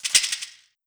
TS - PERC (9).wav